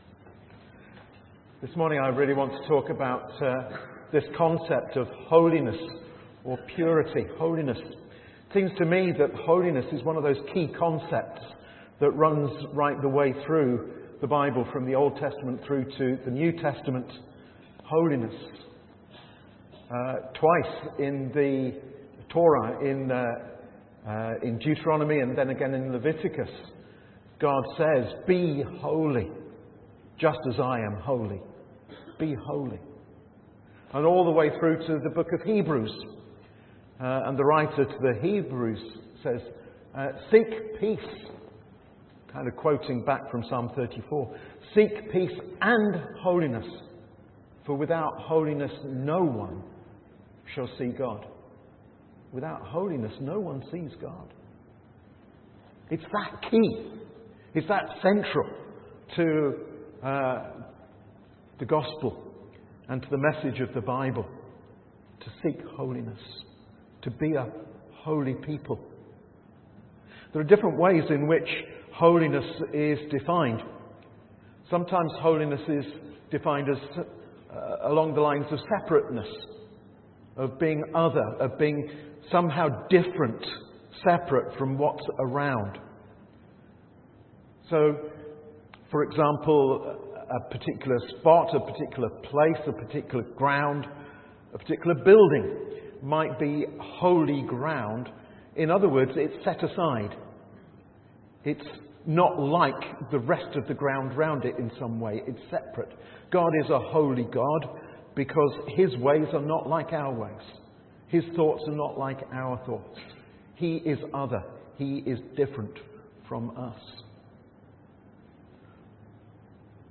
A message from the series
From Service: "9.00am Service"